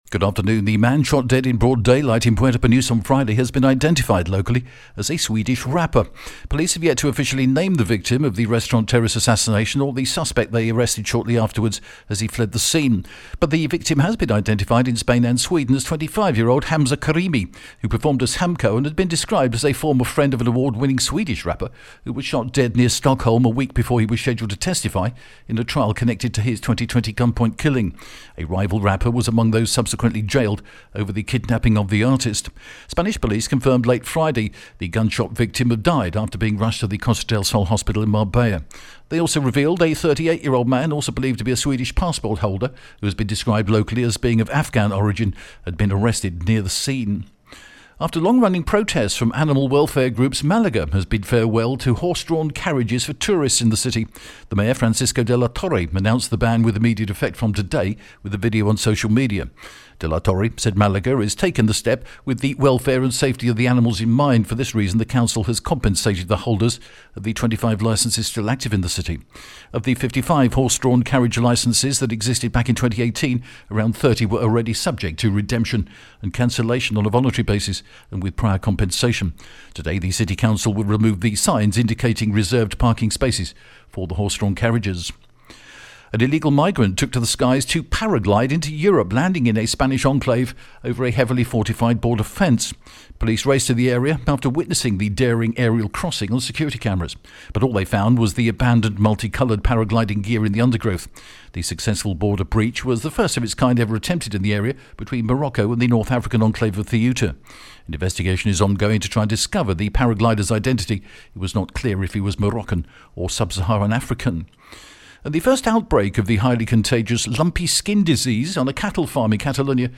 TRE is the only broadcaster in Spain to produce and broadcast, twice daily its own, in house, Spanish and local news service in English...and we offer this to you as a resource right here, and on the hour at tre.radi...